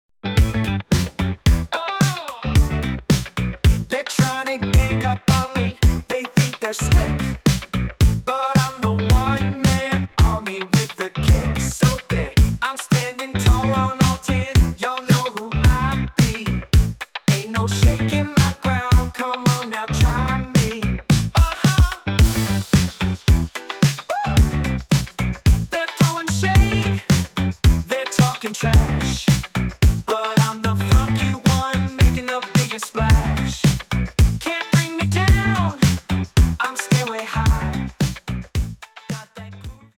An incredible Funk song, creative and inspiring.